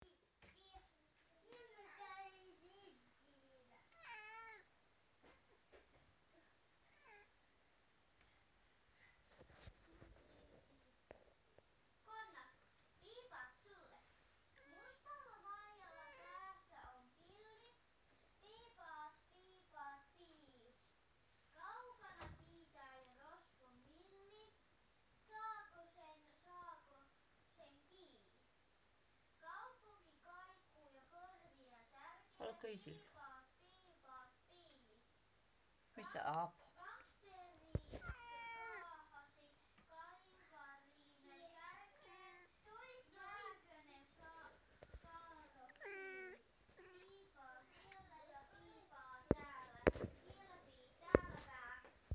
发情的猫
她在楼梯上跑来跑去，从门到门，但毫无收获。免责声明：在录制这个声音时，没有猫受伤;) 用索尼PCM D50录制，内置麦克
标签： 猫哭 MIAU 尖叫 抱怨
声道立体声